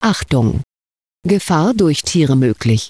Für die Stereo-Dateien gelten folgende Eckdaten: # 16bit # 44100Hz Für die Mono-Dateien gelten folgende Eckdaten: # 16bit # 22050Hz (Klang noch akzeptabel)
Tiere01_mo.wav